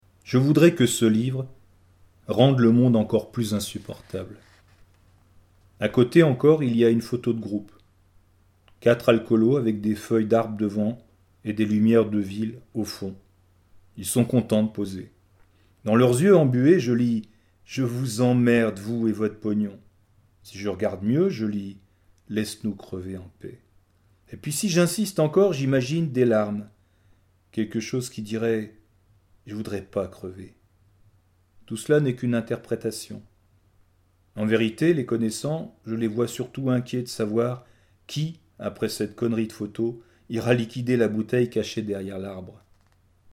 En voix